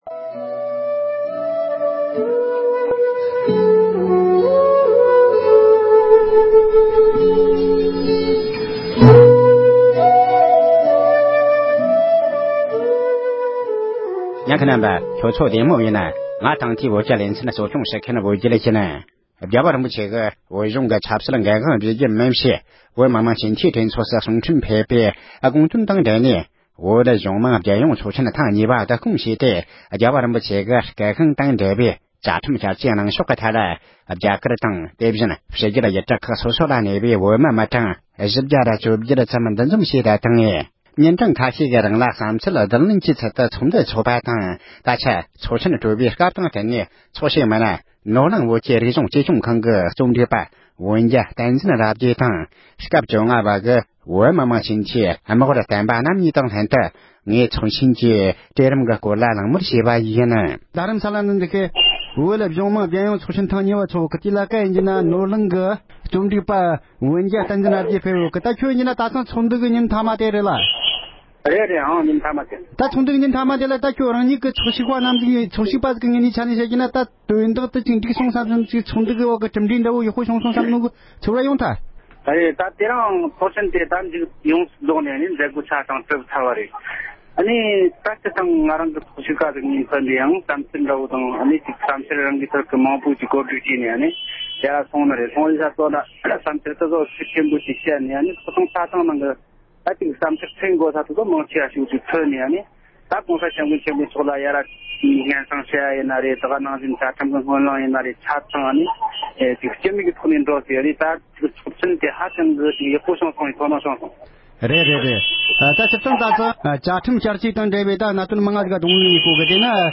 བཙན་བྱོལ་བོད་མིའི་བཅའ་ཁྲིམས་བསྐྱར་བཅོས་ཐད་གླེང་མོལ།